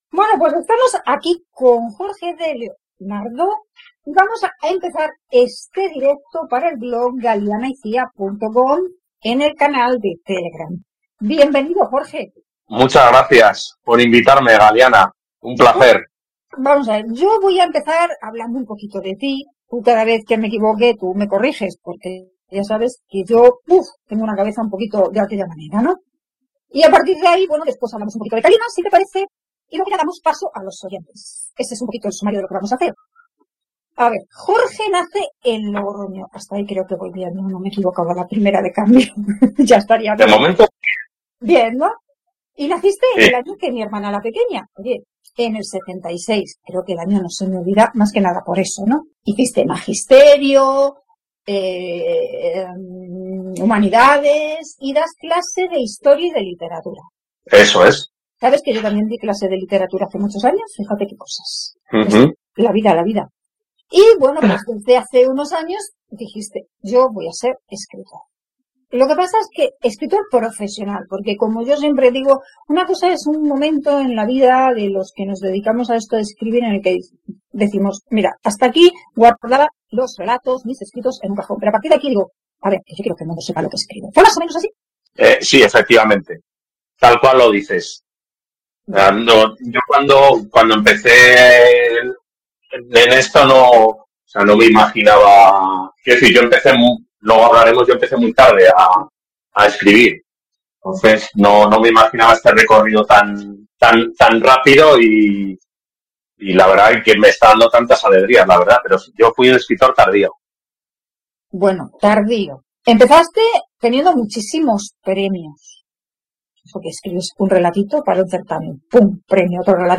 Puedes escuchar el directo de sobre Calima realizado en septiembre de 2025